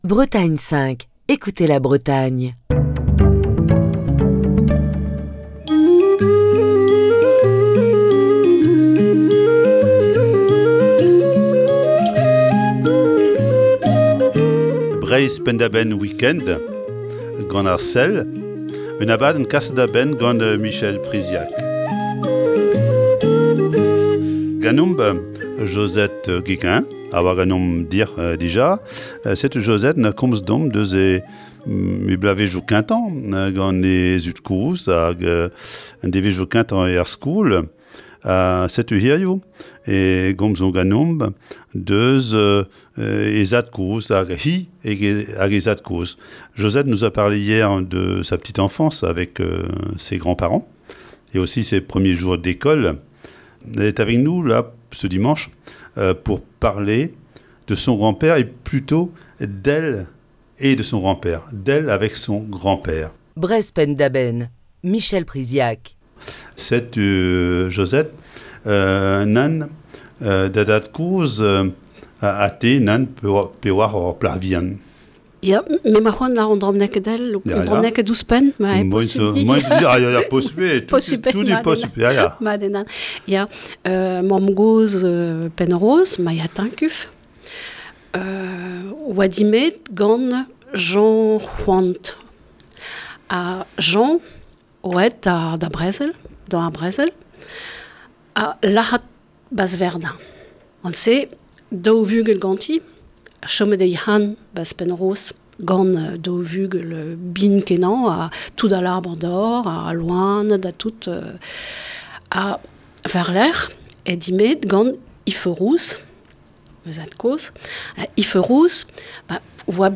Émission du 7 novembre 2021.